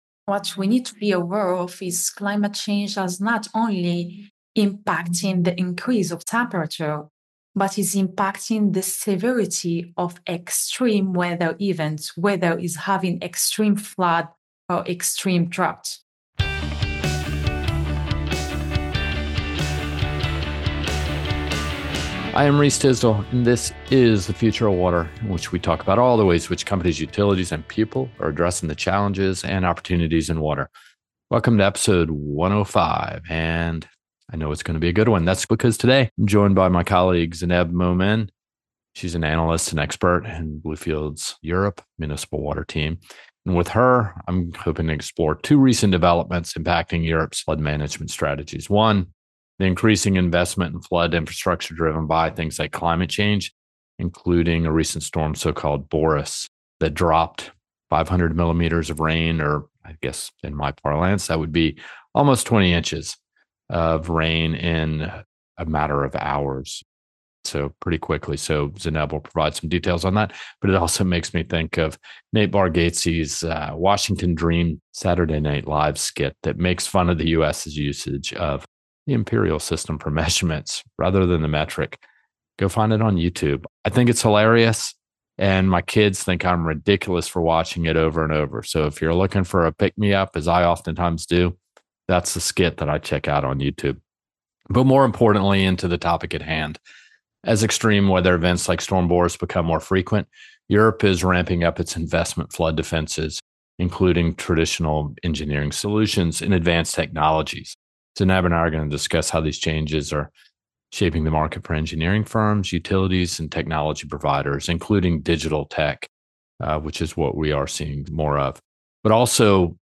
With climate change accelerating the frequency and intensity of extreme weather events, countries across the continent are grappling with the need for resilient infrastructure. This conversation couldn’t be more timely: recently, Storm Boris brought nearly 500mm of rainfall—almost 20 inches—across Central Europe, testing current flood management defenses and highlighting critical gaps.